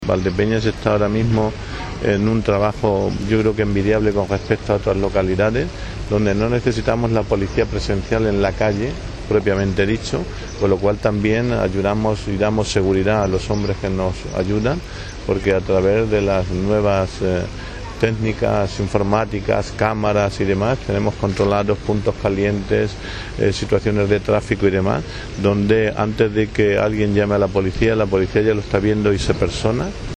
>> Lo ha adelantado el alcalde, Jesús Martín, durante los actos de la festividad del patrón del Cuerpo, Santo Ángel de la Guarda
cortealcaldeseguridad.mp3